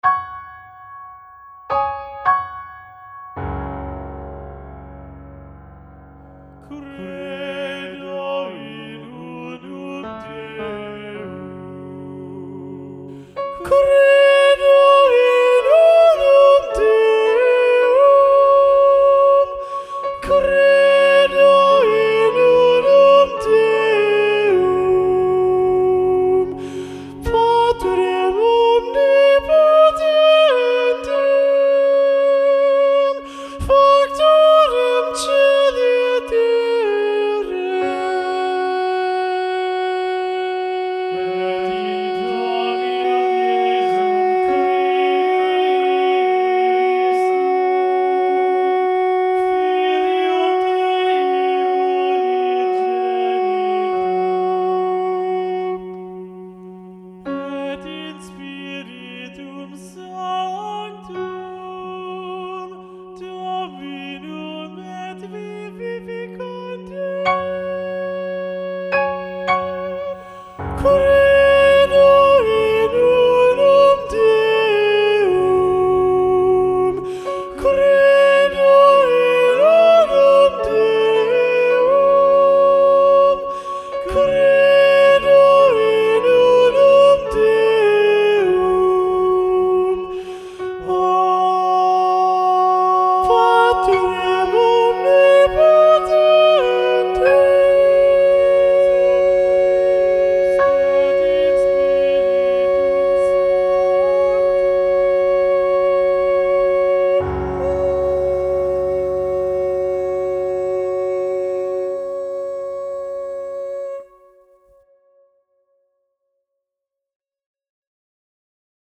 Lo ULTIMO sopranos
Credo-Missa-Festiva-SATB-Soprano-Predominant-John-Leavitt.mp3